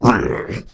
c_ogtzom_hit2.wav